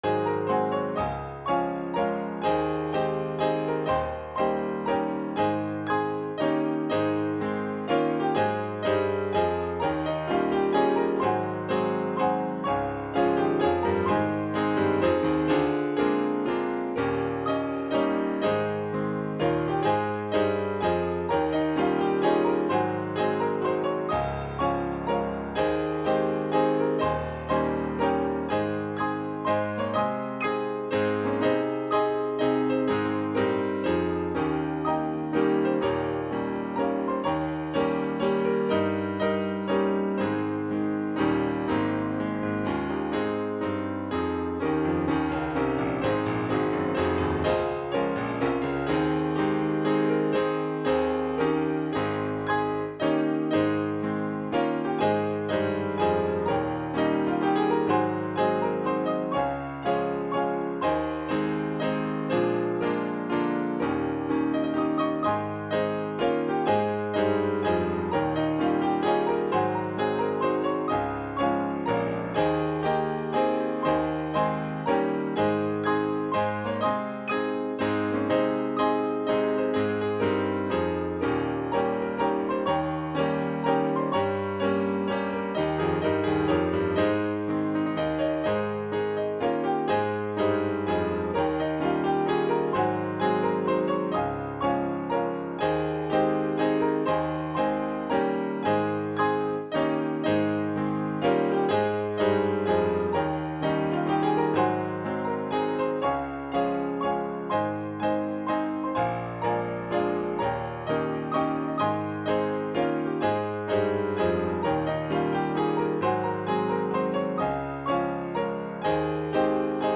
Key: A♭